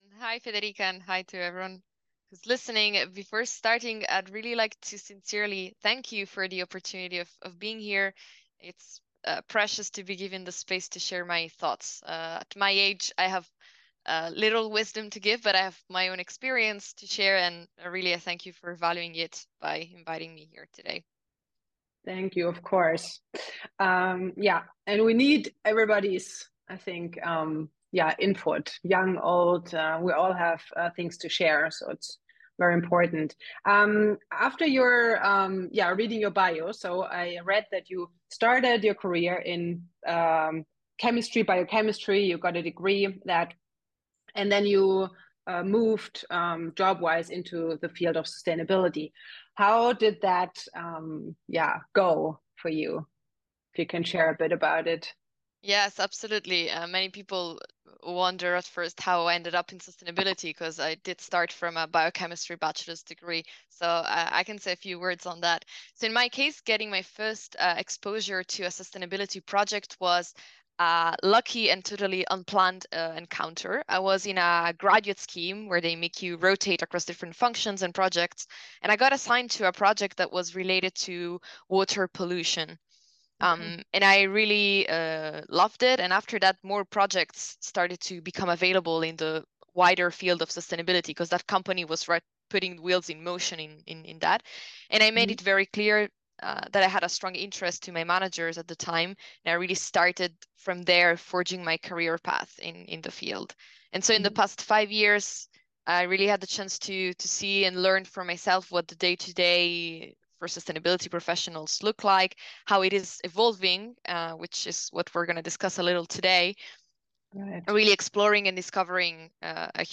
Listen to the conversation How did you build your career in sustainability from a Biochemistry degree?